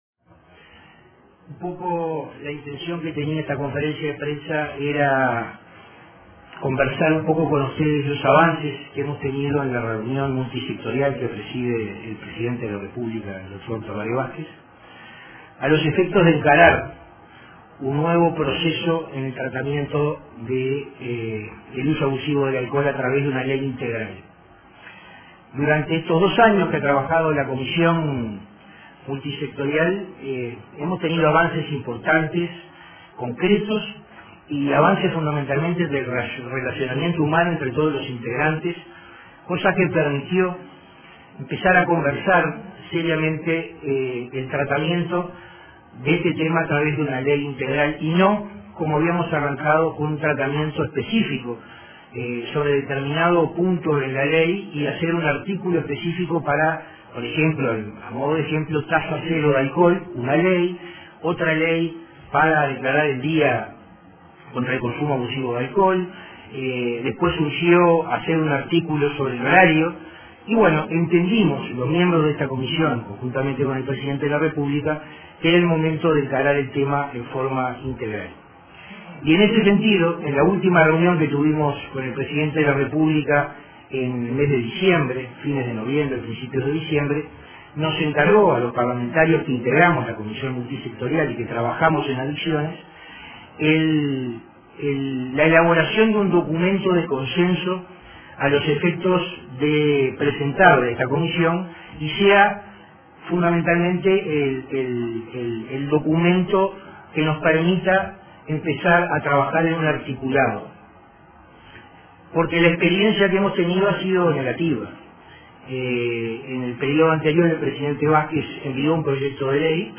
Comisión para regular consumo de alcohol elaboró documento de consenso para futura ley integral 14/03/2017 Compartir Facebook X Copiar enlace WhatsApp LinkedIn Los legisladores que integran la comisión para la regulación del consumo de alcohol, Luis Gallo del Frente Amplio, Daniel Radío del Partido Independiente y Verónica Alonso del Partido Nacional, informaron sobre los avances para concretar una ley integral en la materia. Los tres parlamentarios detallaron a la prensa los alcances de un documento de consenso que permitirá trabajar en el articulado del mencionado proyecto de ley.